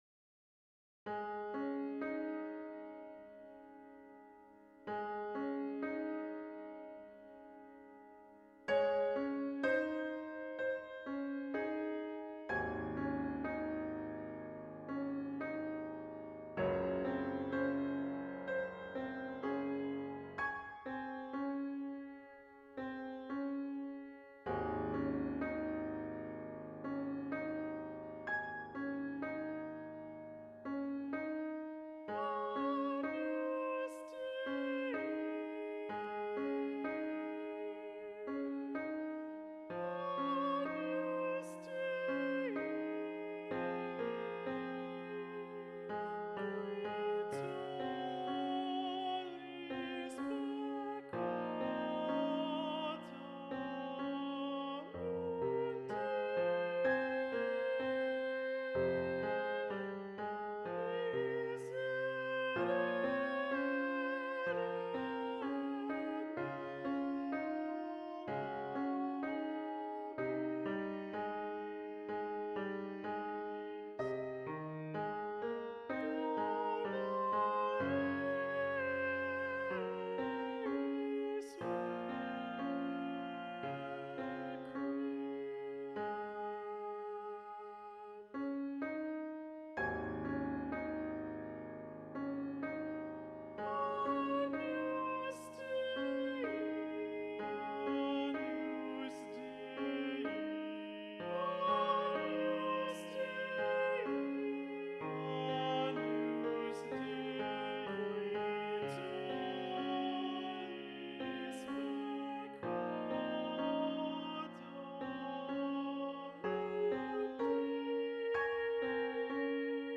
Video Only: Agnus Dei - Bass 1 Predominant